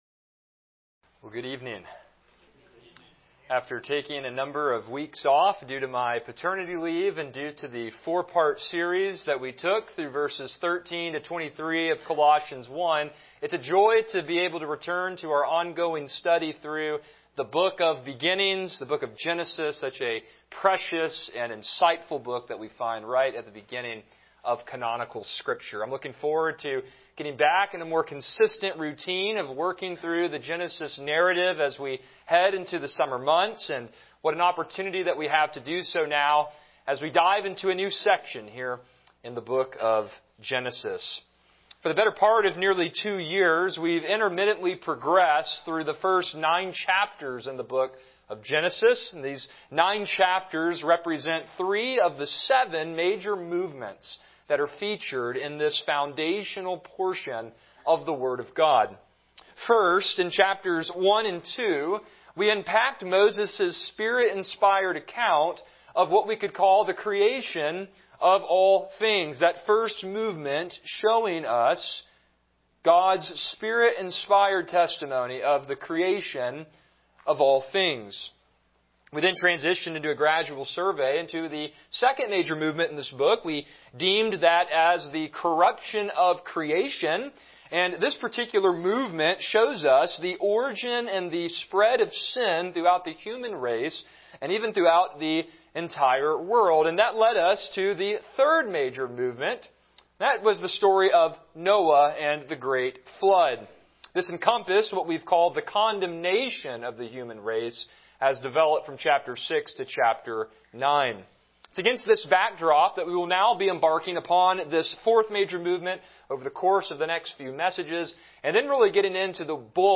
Passage: Genesis 10 Service Type: Evening Worship